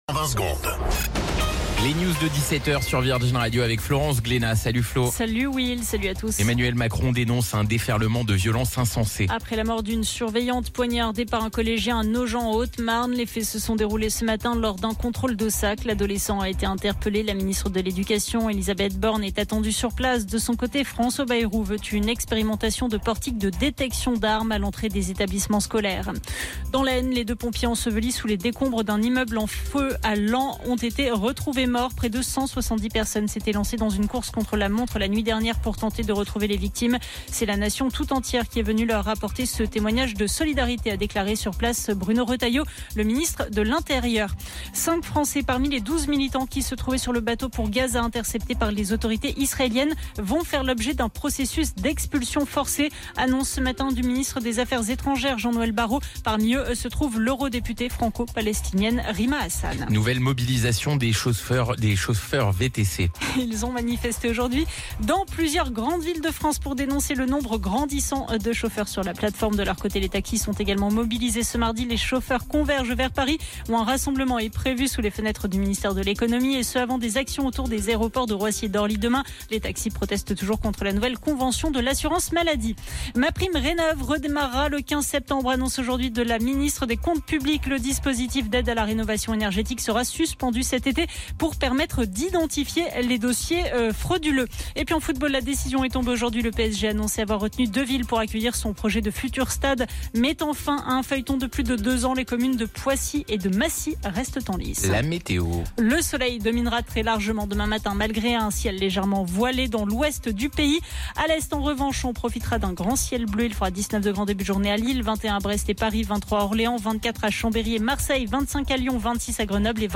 Flash Info National 10 Juin 2025 Du 10/06/2025 à 17h10 .